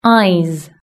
Index of /platform/shared/pron-tool/british-english/sound/words